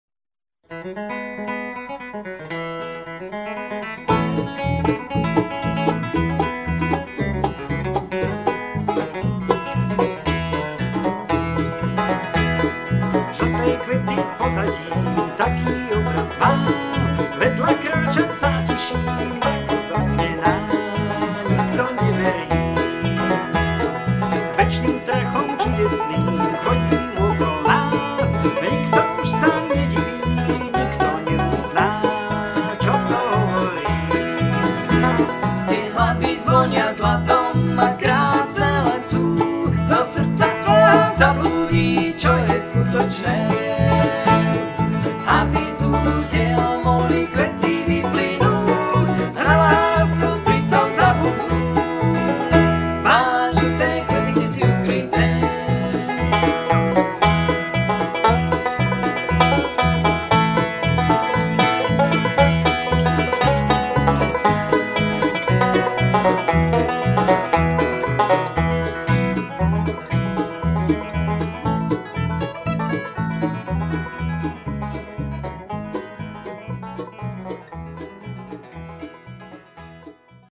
mandolína, gitara, lead, tenor vokál
banjo, tenor vokál
bass, bariton vokál